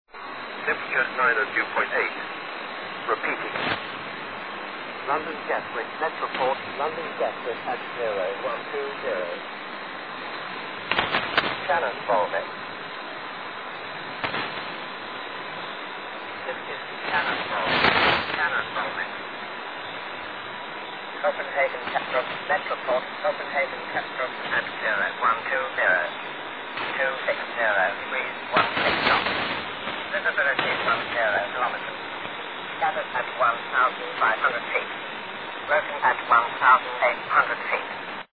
5505 golos